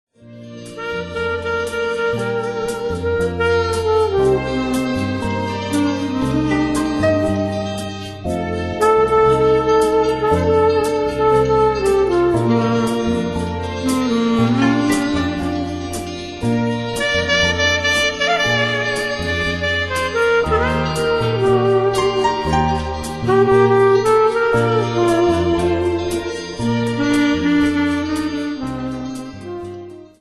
アルトサックスとソプラノ琴が
※視聴できる曲はＨＰ用に加工してあります。実際のＣＤの音とは多少異なります。